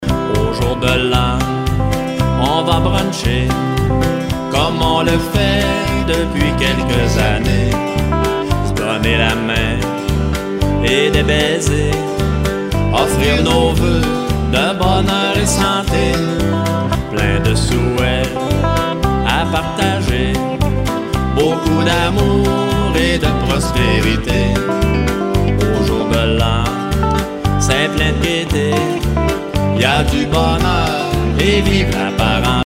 Enregistrement au studio